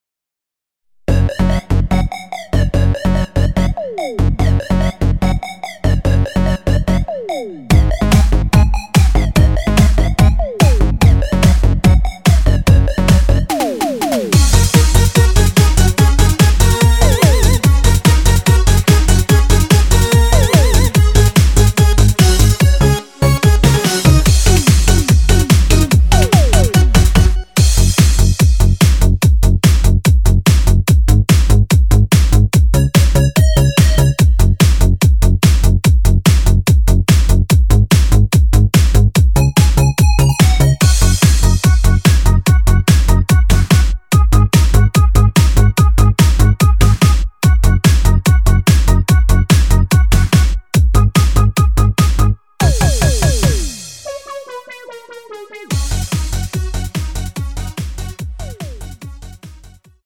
공식 음원 MR
F#
앞부분30초, 뒷부분30초씩 편집해서 올려 드리고 있습니다.
중간에 음이 끈어지고 다시 나오는 이유는